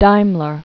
(dīmlər), Gottlieb 1834-1900.